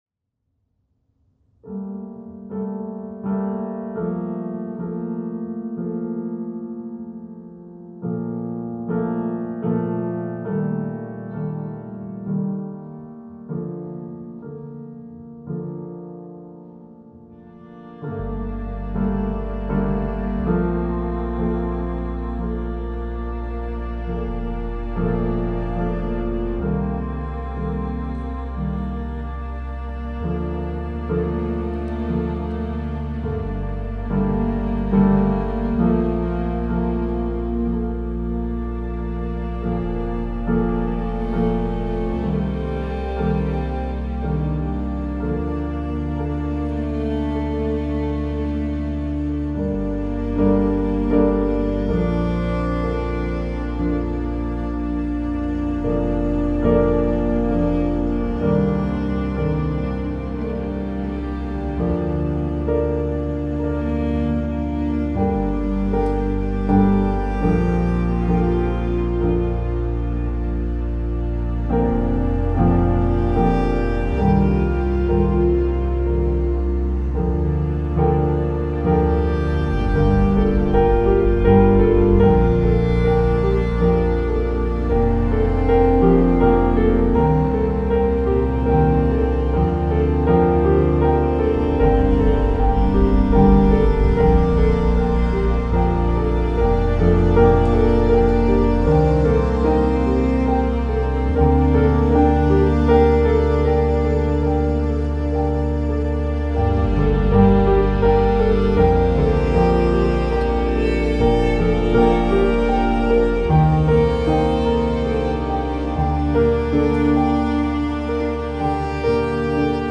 موسیقی کنار تو
موسیقی بی کلام امبینت پیانو غم‌انگیز مدرن کلاسیک